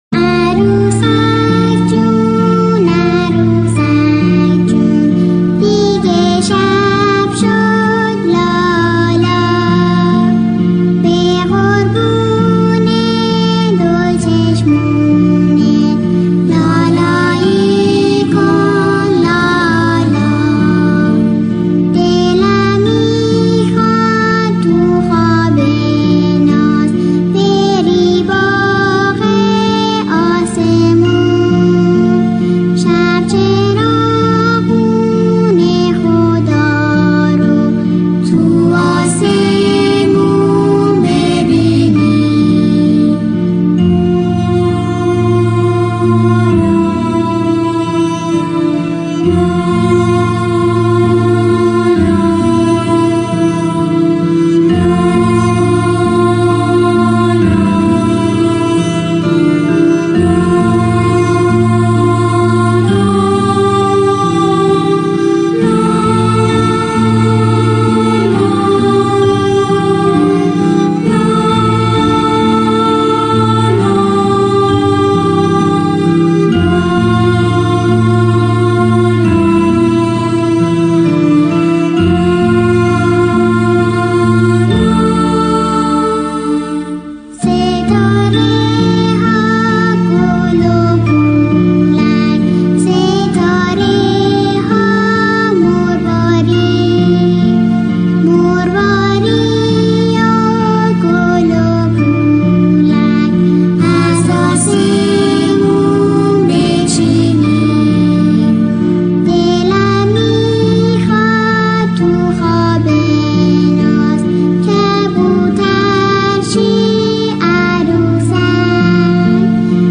آهنگ لالایی